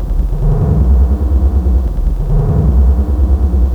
• analog bass background endless Fm.wav
Sound designed using multiple effects processors and gaters, applied on modular gear (Korg)